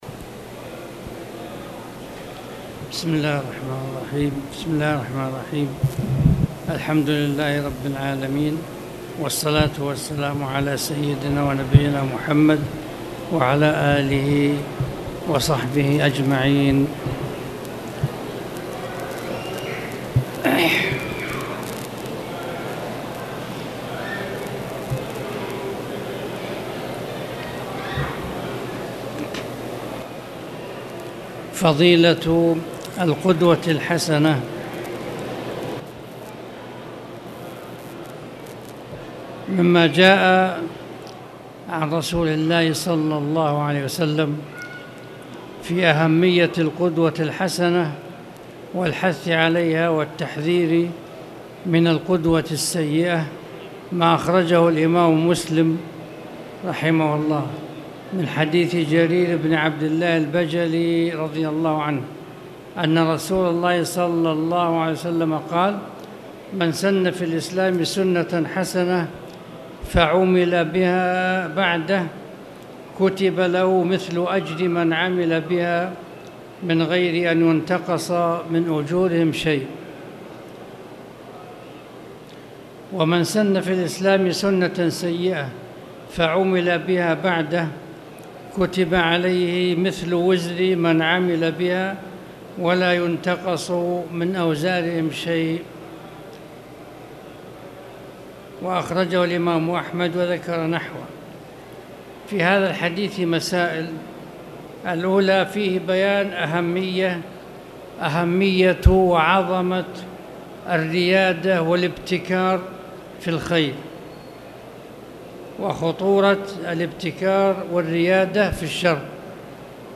تاريخ النشر ١٩ صفر ١٤٣٨ هـ المكان: المسجد الحرام الشيخ